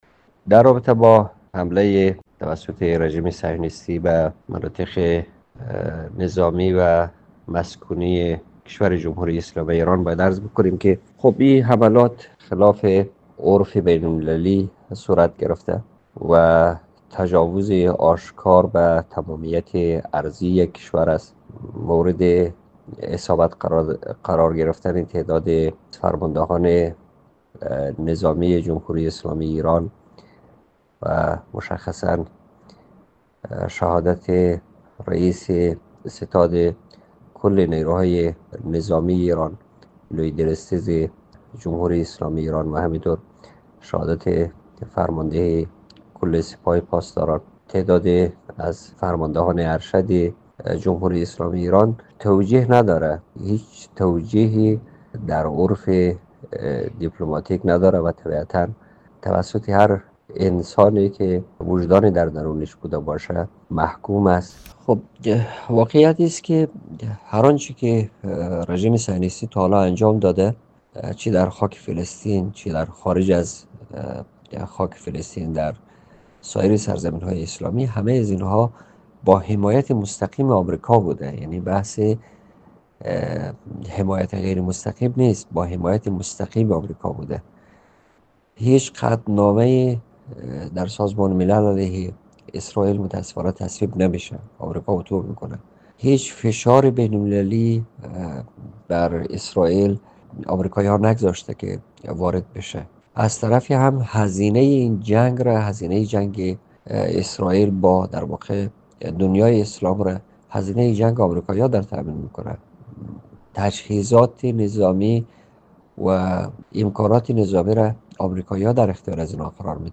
حیات‌الله عالمی، نماینده سابق پارلمان افغانستان، در گفت‌وگو با رادیو دری حملات رژیم صهیونیستی به ایران را نقض قوانین بین‌المللی و تجاوز به تمامیت ارضی یک ک...
مصاحبه